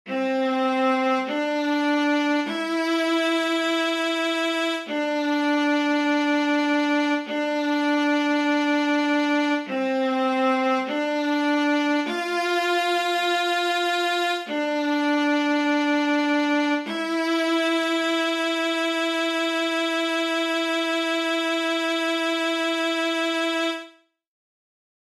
How many parts: 8
Type: Barbershop
Each recording below is single part only.
Other part 1: